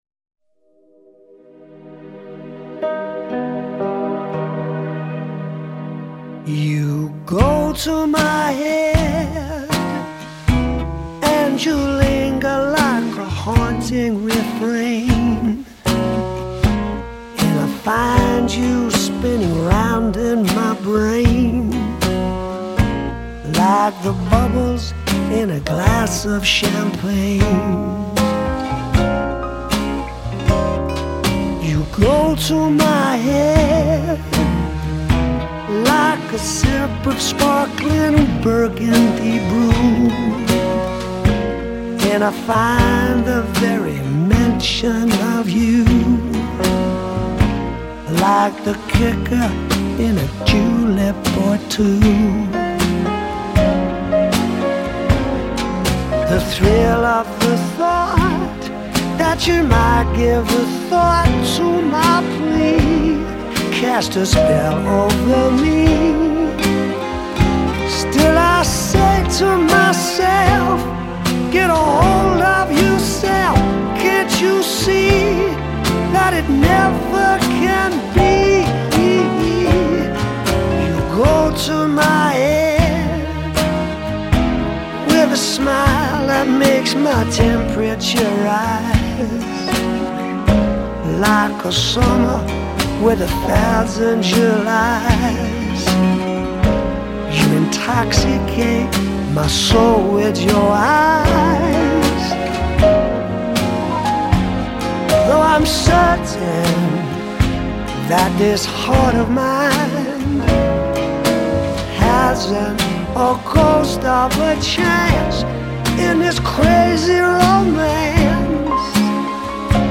西洋音樂